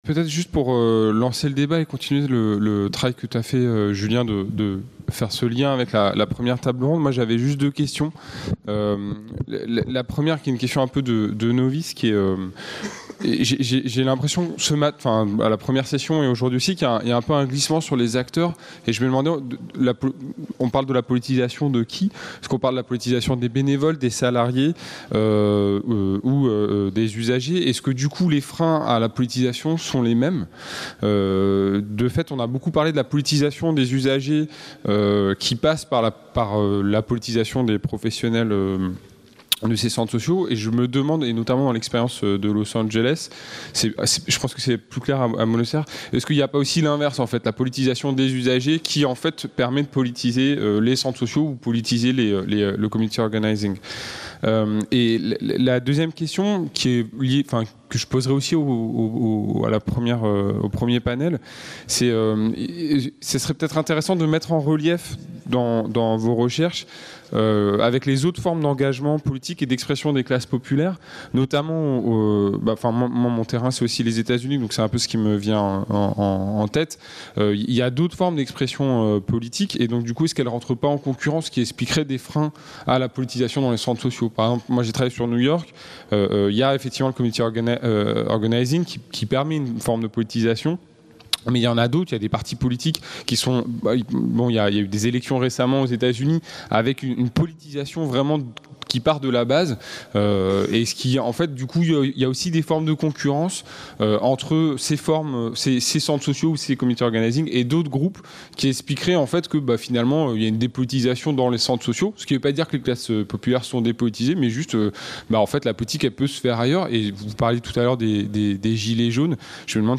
18- (Table ronde 3) : Débat avec le public (5) | Canal U